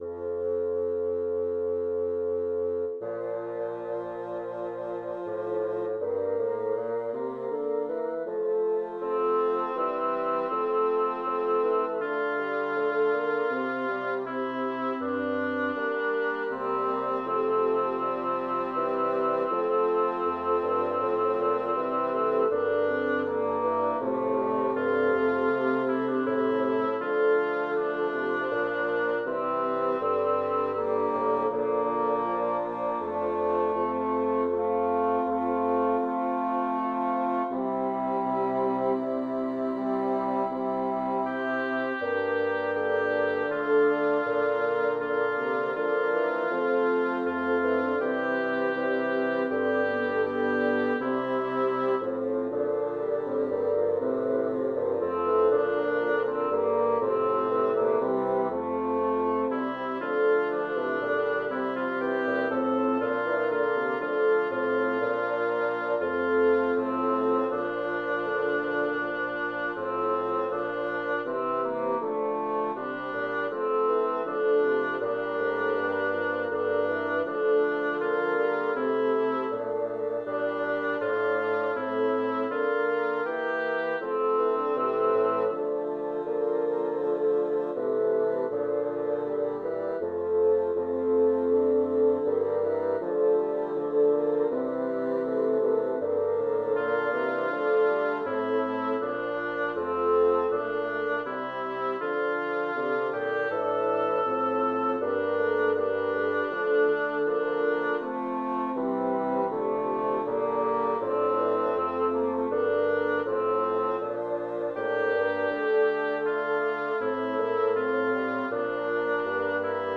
Title: Exaudi domine vocem meam Composer: Francesco Stivori Lyricist: Number of voices: 5vv Voicing: ATTTB Genre: Sacred, Motet
Language: Latin Instruments: A cappella